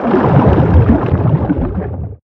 Sfx_creature_shadowleviathan_swimfast_04.ogg